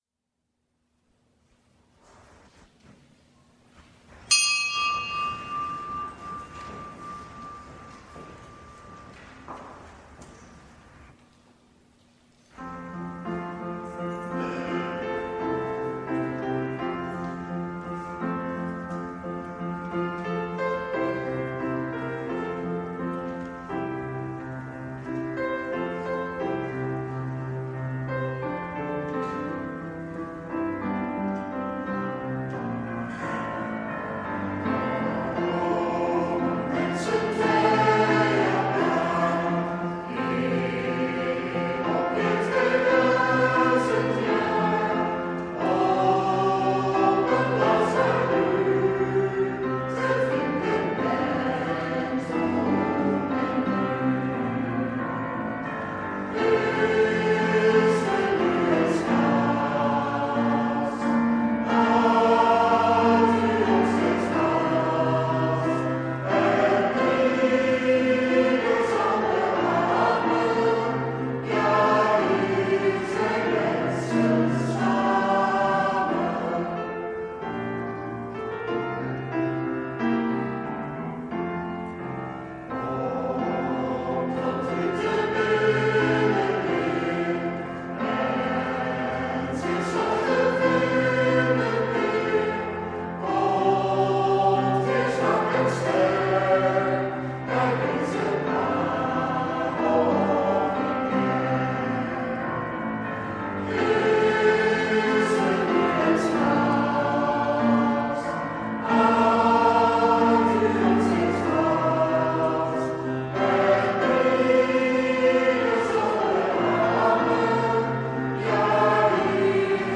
Eucharistieviering beluisteren vanuit de Sint Jozef te Wassenaar (MP3)